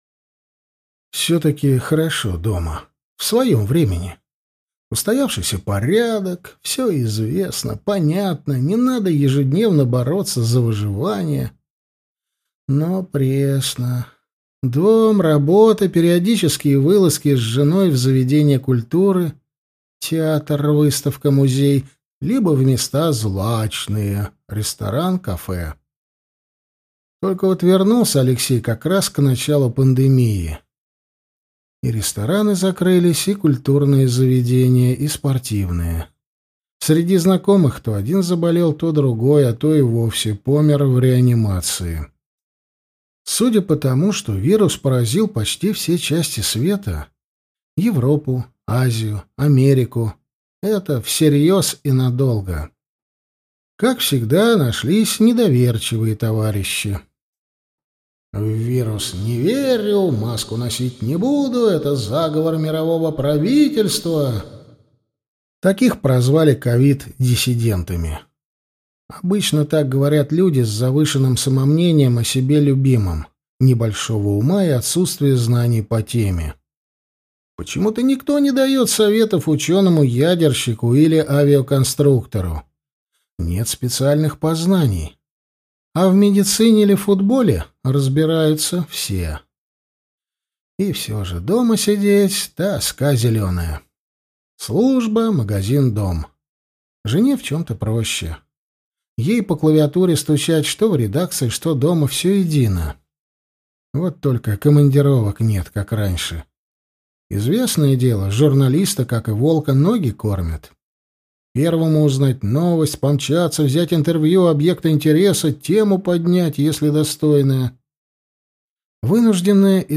Аудиокнига Нашествие. Попаданец во времена Отечественной войны 1812 года | Библиотека аудиокниг